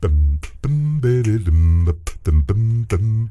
ACCAPELLA 9A.wav